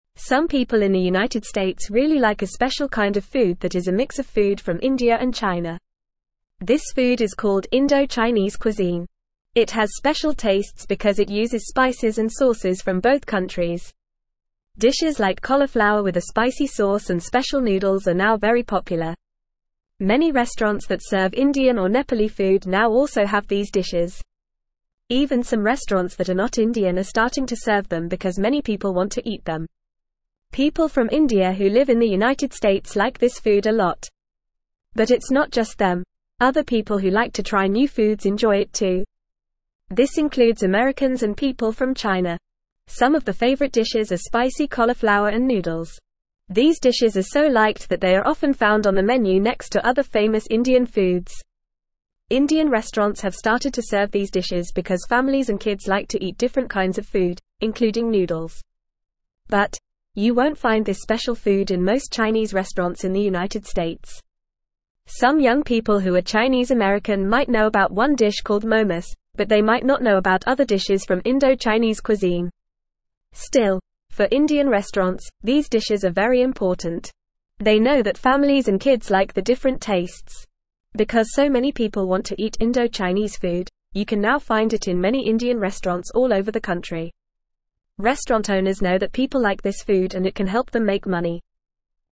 Fast
English-Newsroom-Lower-Intermediate-FAST-Reading-Indo-Chinese-Food-A-Tasty-Mix-of-India-and-China.mp3